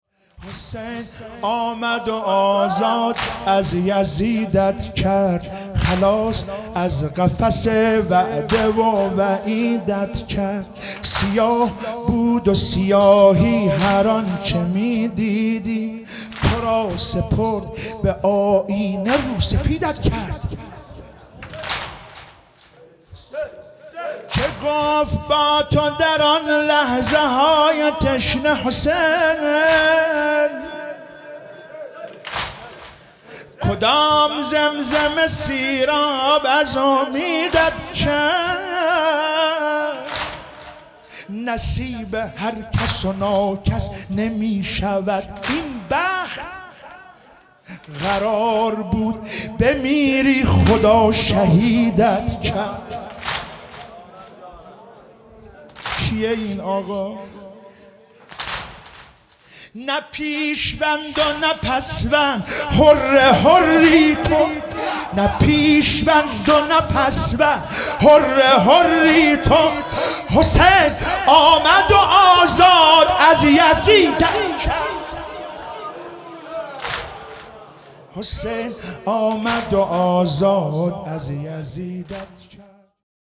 شب چهارم محرم96 - واحد - حسین آمد و آزاد از یزیدت کرد